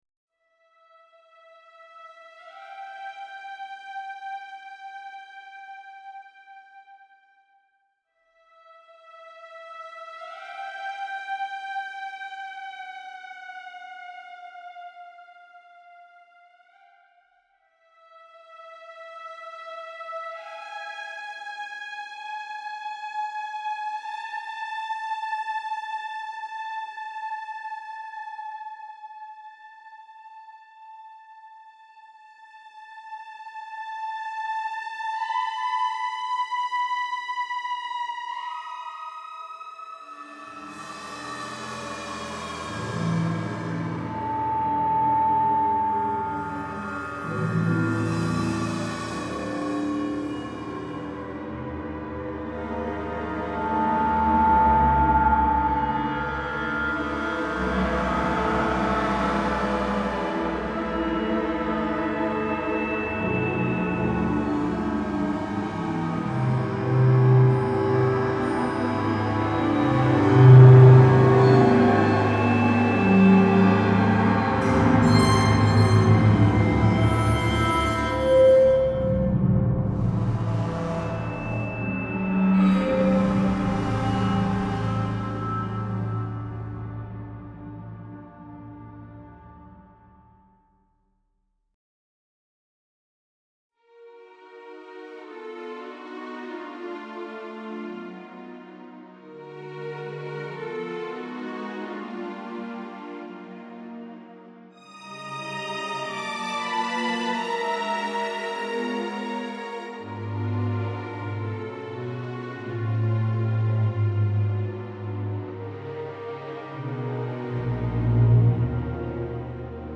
Ceci étant j'aime pas les pitchs, mais il y a bcp de couleurs là dedans, et j'ador les mouvements des cordes .
Fabulous sounds and atmosphère. J'aime le mystery ici. 17/20